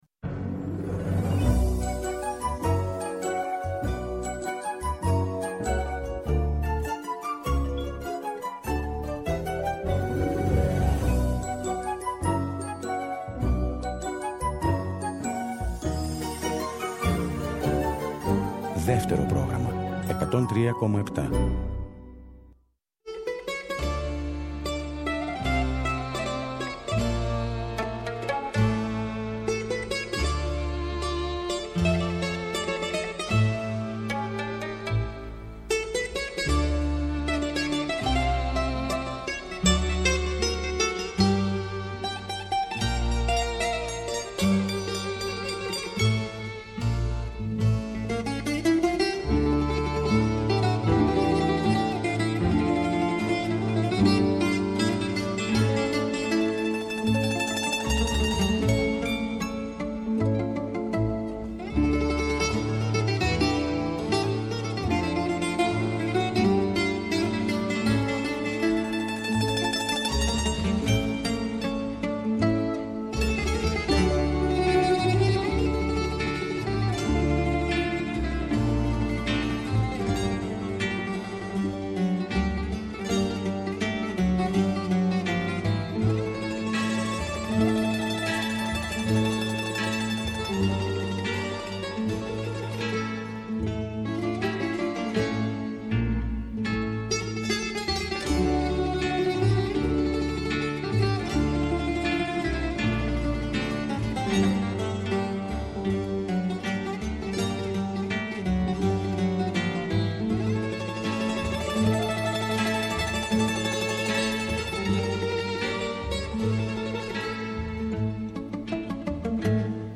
καλεσμένος στο στούντιο του Δεύτερου Προγράμματος